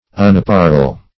What does unapparel mean? Meaning of unapparel. unapparel synonyms, pronunciation, spelling and more from Free Dictionary.
Search Result for " unapparel" : The Collaborative International Dictionary of English v.0.48: Unapparel \Un`ap*par"el\, v. t. [1st pref. un- + apparel.]